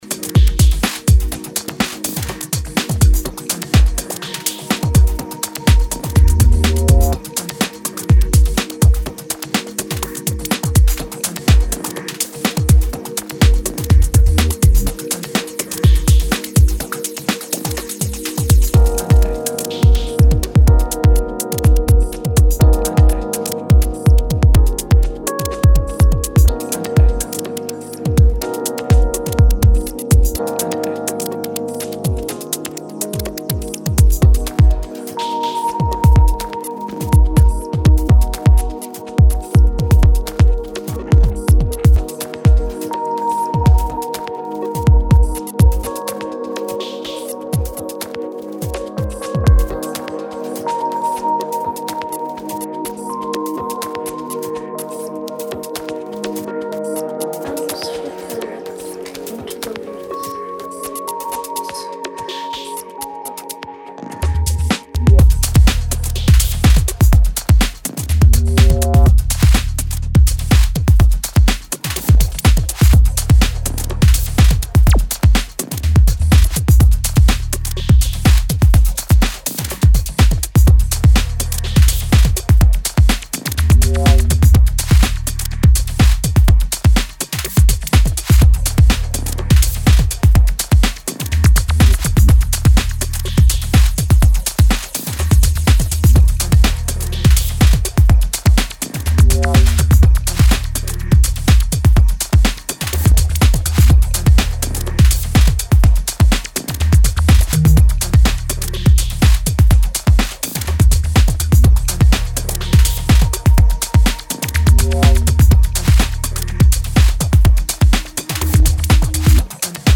intergalactic breakbeat piece